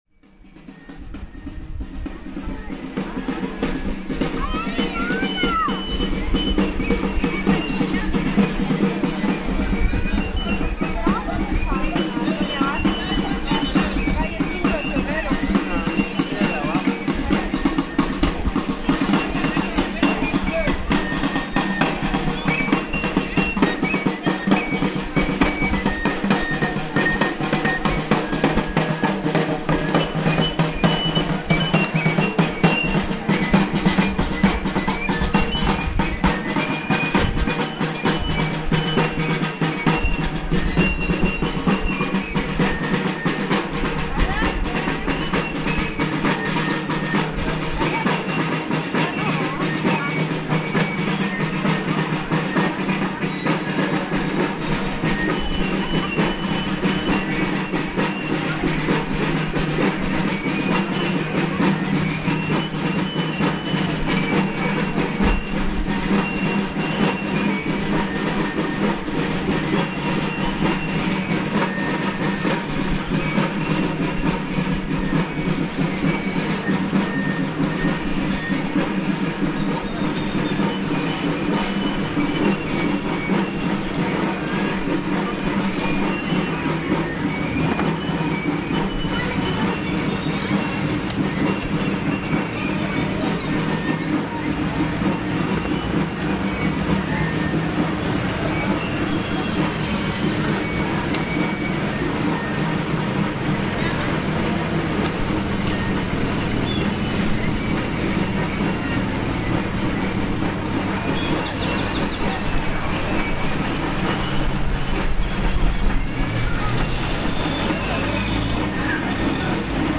Música de tambor
La música del tambor y el pito resuenan en las calles de la Heróica Chiapa de Corzo, una de las ciudades coloniales más importantes del estado de Chiapas, Mexico.
En diversos días del año gran número de personas caminan por las calles acompañados de música, esta procesión es conocida como Anuncio, cuya finalidad es avisar a la gente que ya está por celebrarse la fiesta del Santo.
Caminemos al compás del Pájaro Chogui, melodía adaptada e interpretada por estos músicos tradicionales.